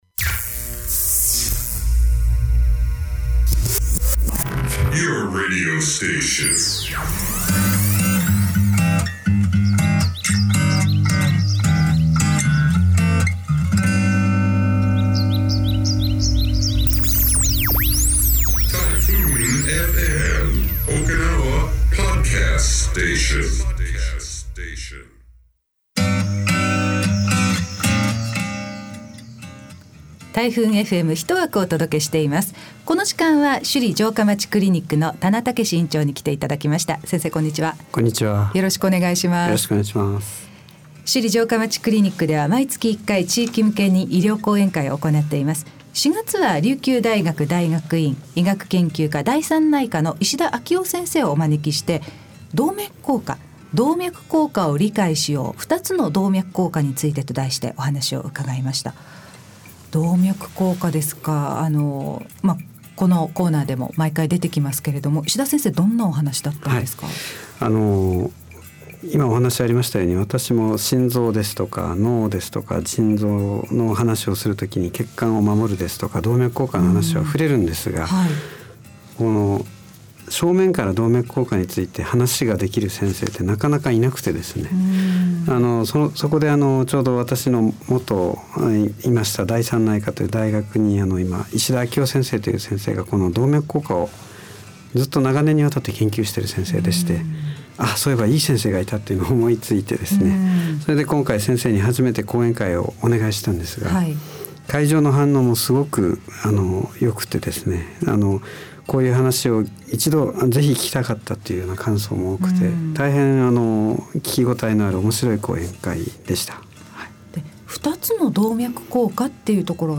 140502首里城下町クリニック 地域向け医療講演会『動脈硬化を理解しよう〜2つの動脈硬化について〜』